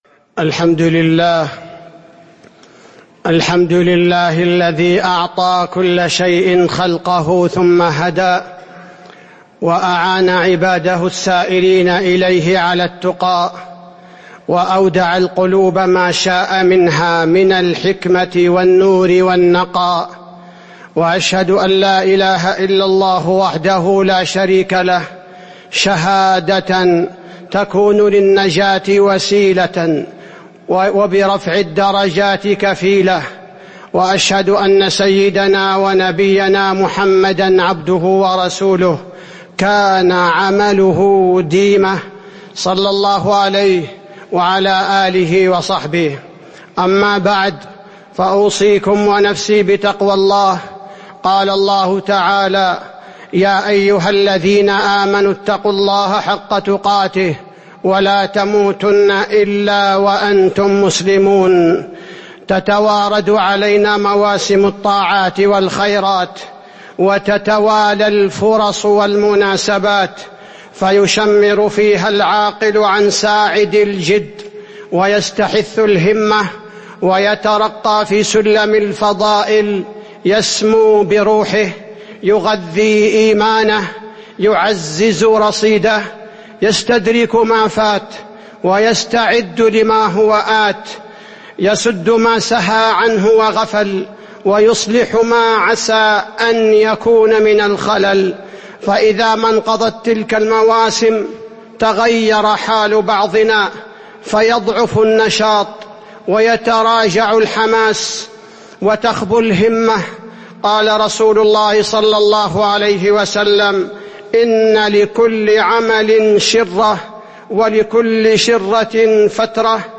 تاريخ النشر ١٢ ذو الحجة ١٤٤٤ هـ المكان: المسجد النبوي الشيخ: فضيلة الشيخ عبدالباري الثبيتي فضيلة الشيخ عبدالباري الثبيتي فضل المداومة على الأعمال الصالحة